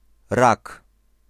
Ääntäminen
Ääntäminen Tuntematon aksentti: IPA: [rak] Haettu sana löytyi näillä lähdekielillä: puola Käännös Konteksti Erisnimet 1. constellation astronomia 2.